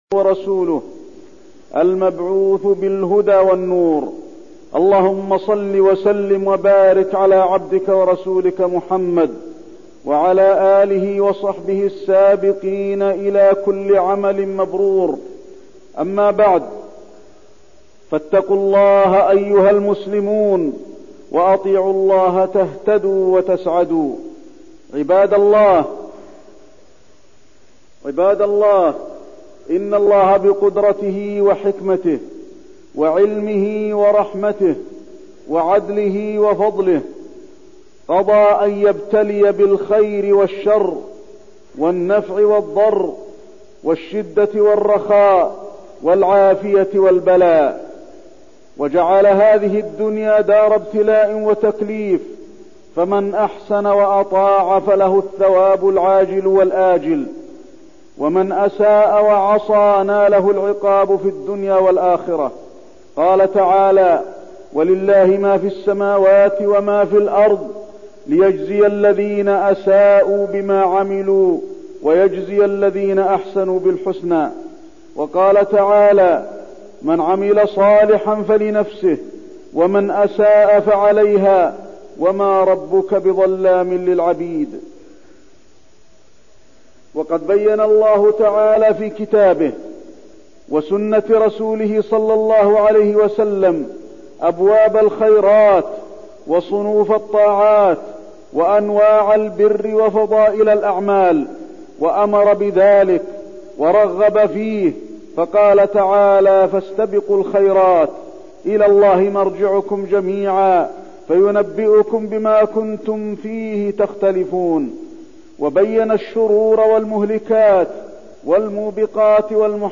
تاريخ النشر ٢٤ رجب ١٤١١ هـ المكان: المسجد النبوي الشيخ: فضيلة الشيخ د. علي بن عبدالرحمن الحذيفي فضيلة الشيخ د. علي بن عبدالرحمن الحذيفي جزاء الأعمال